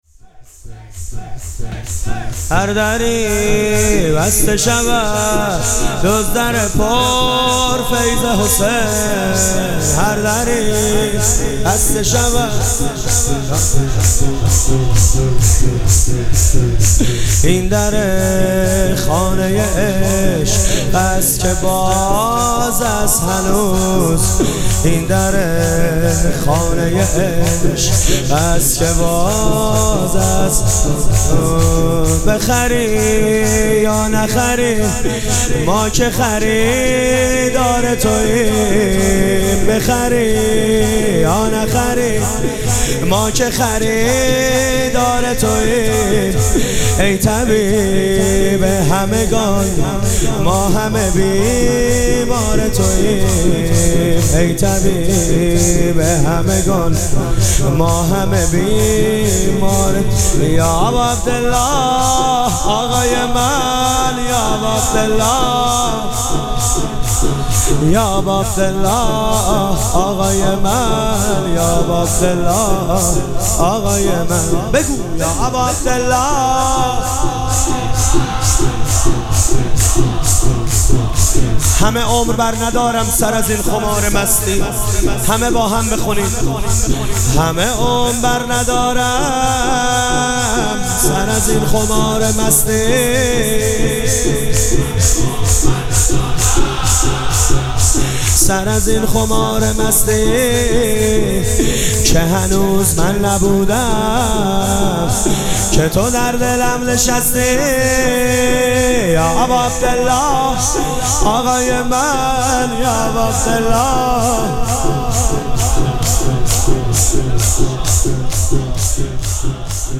شب چهارم مراسم عزاداری اربعین حسینی ۱۴۴۷
شور